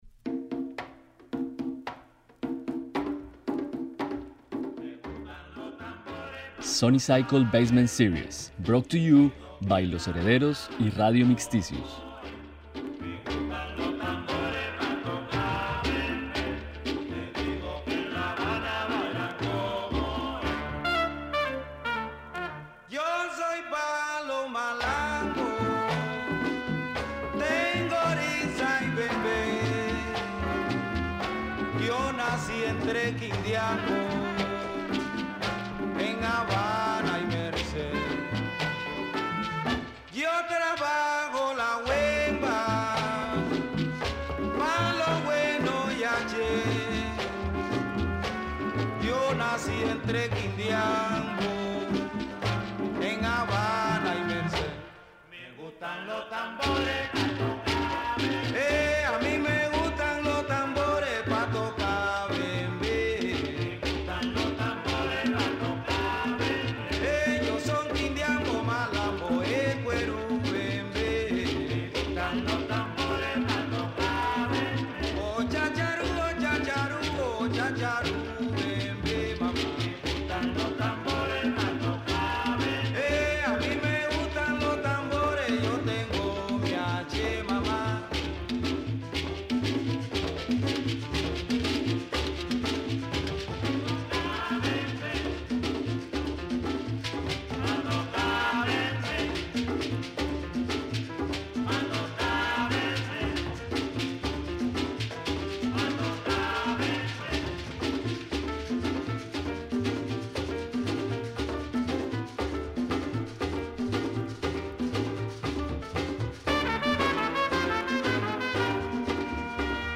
DJ Set